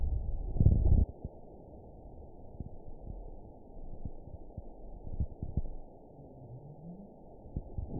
event 920470 date 03/27/24 time 01:32:31 GMT (1 year, 1 month ago) score 8.62 location TSS-AB03 detected by nrw target species NRW annotations +NRW Spectrogram: Frequency (kHz) vs. Time (s) audio not available .wav